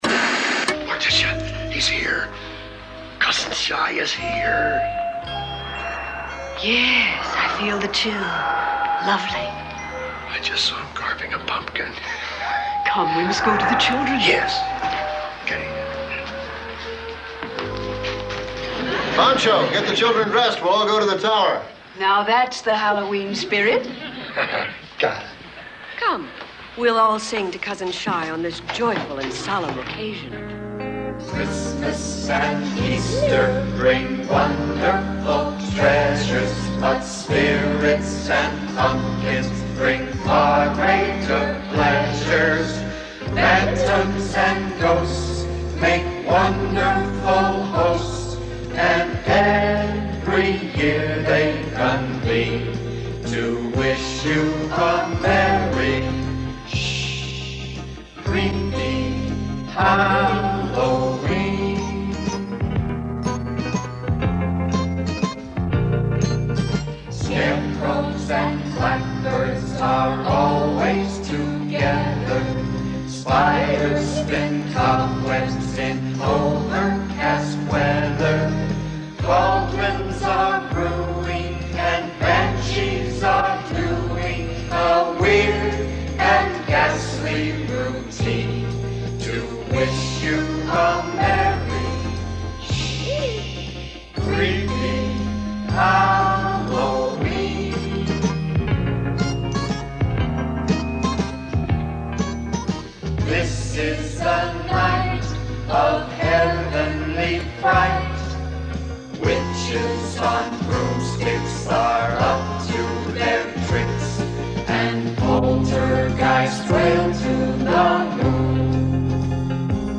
Christmas-like carol